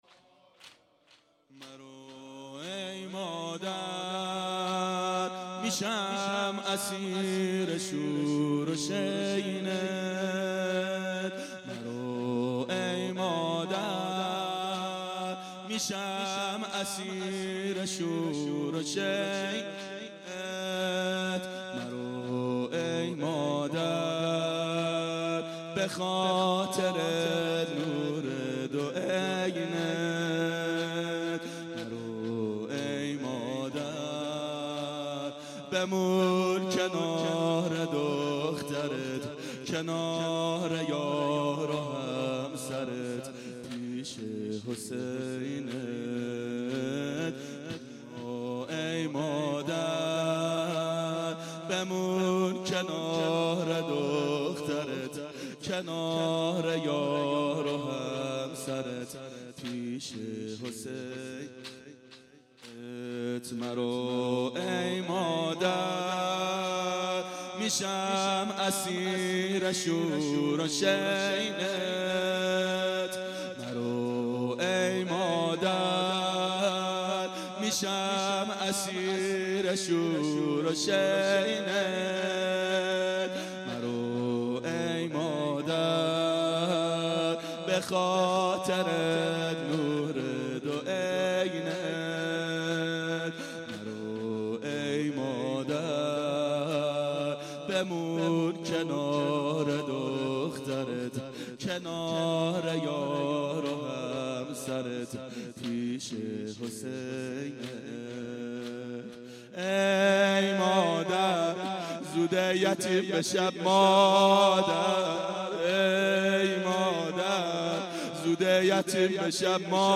• دهه اول صفر سال 1392 هیئت شیفتگان حضرت رقیه سلام الله علیها